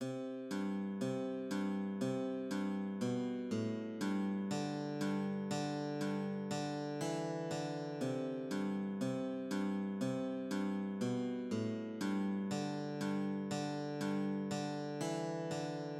Walking bass often alternates quarter notes:
Walking_bass_I-V.mid.mp3